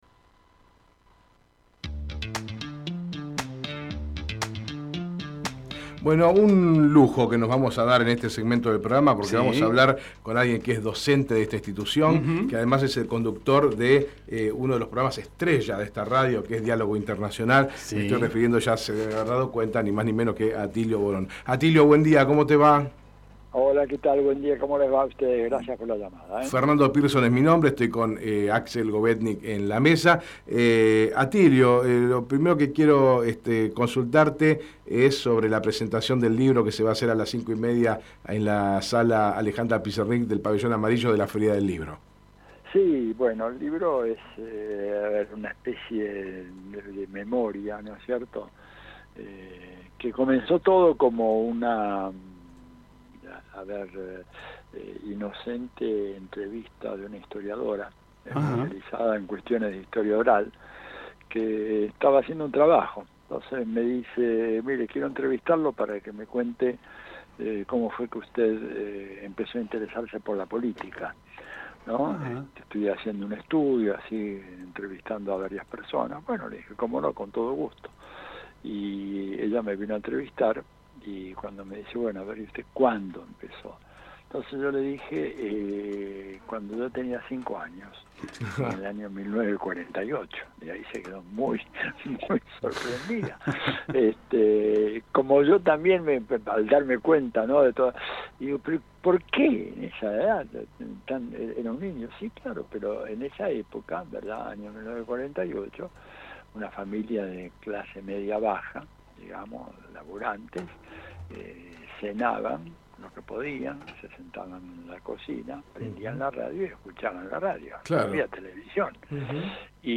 Atilio Borón en Hacemos PyE Texto de la nota: Compartimos la entrevista realizada en Hacemos PyE con Atilio Borón , sociólogo, politólogo, analista internacional, docente y Director de CCC de Licenciatura en Historia Latinoamericana de la UNDAV. Conversamos sobre la presentación de su libro en la Feria Internacional del Libro de Buenos Aires.